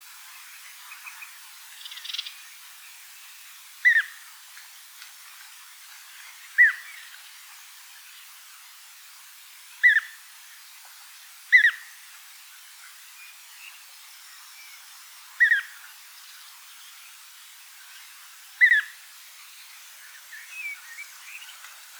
Звуки золотистой щурки
Пение птиц среди роскоши природы